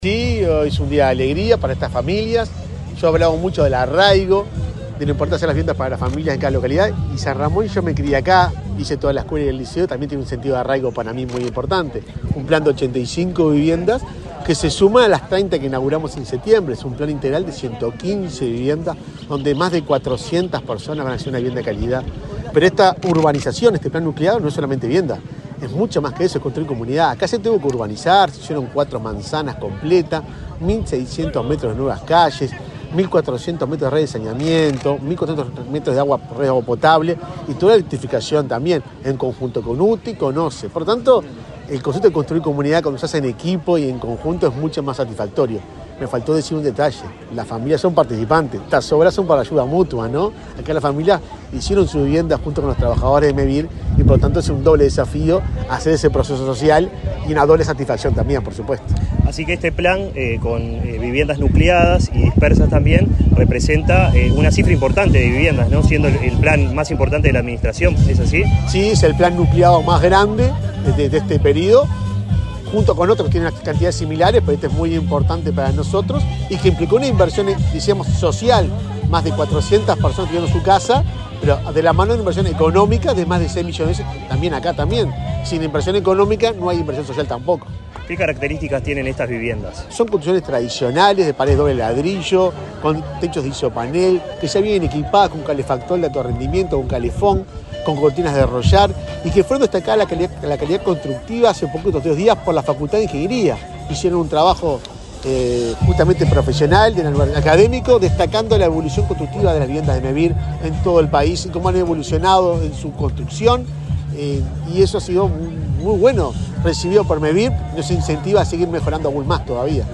Entrevista al presidente de Mevir, Juan Pablo Delgado